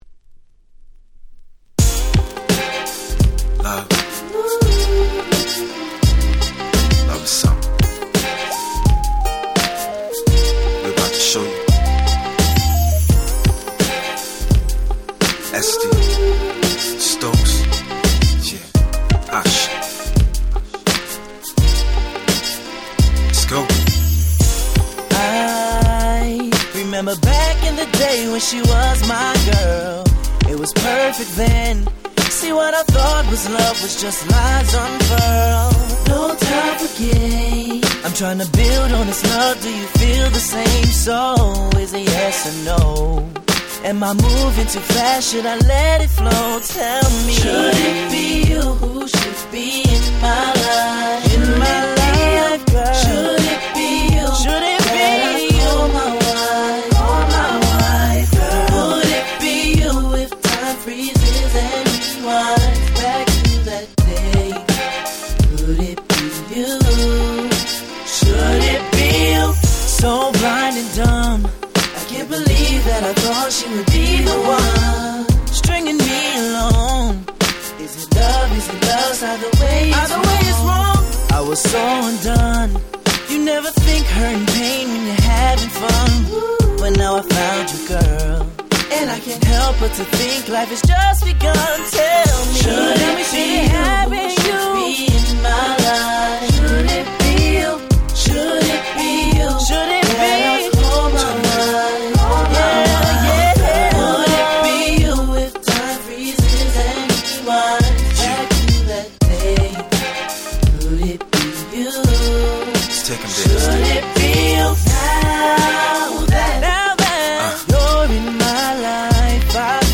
07' Nice UK R&B !!